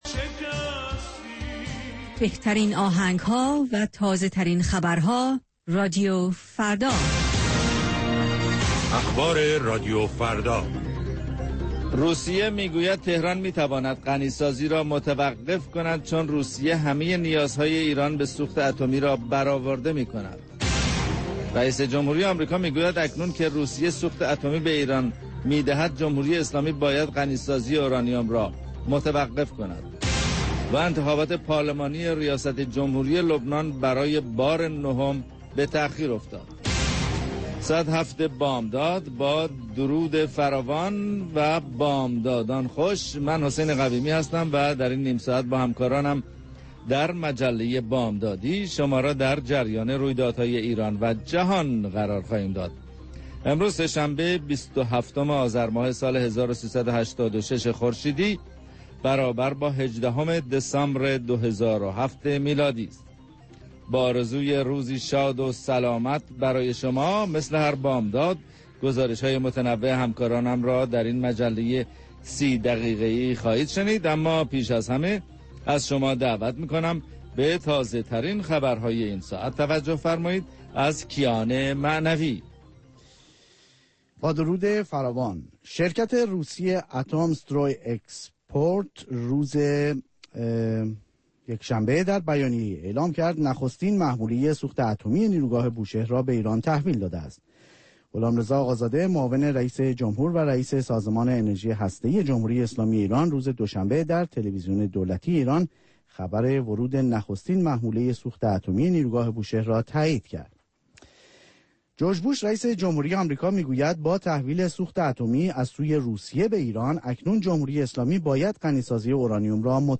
گزارشگران راديو فردا از سراسر جهان، با تازه ترين خبرها و گزارش ها، مجله ای رنگارنگ را برای شما تدارک می بينند. با مجله بامدادی راديو فردا، شما در آغاز روز خود، از آخرين رويدادها آگاه می شويد.